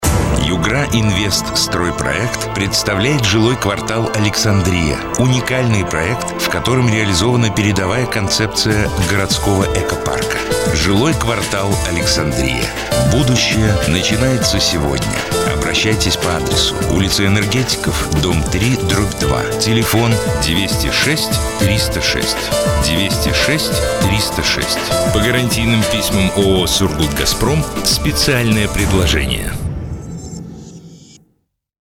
ЖК Александрия - радио реклама